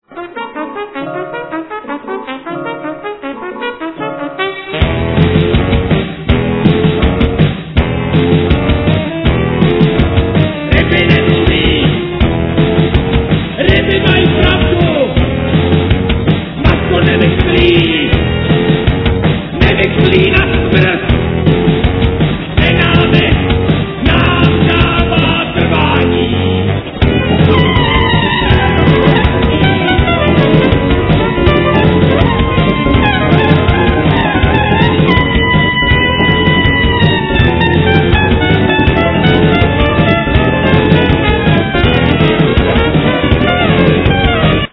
Bass guitar, Sequencer, Sbor
Trumpet
Violin